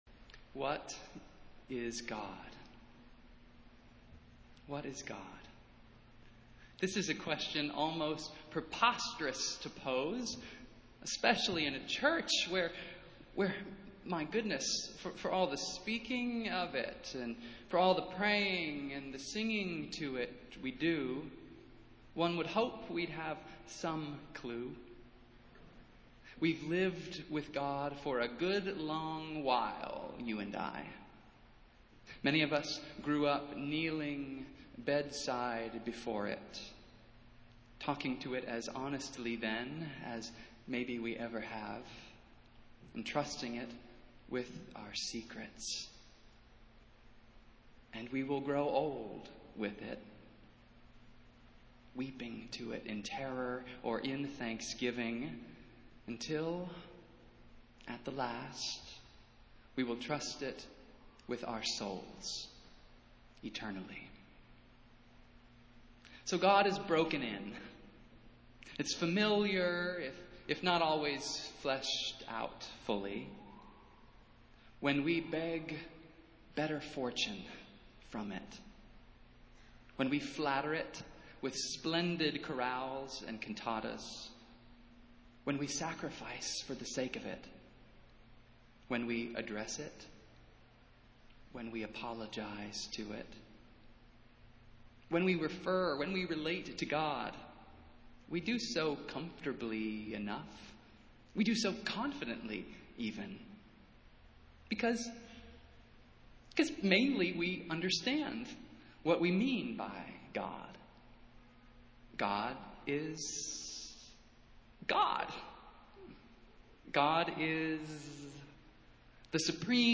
Festival Worship - Second Sunday after Pentecost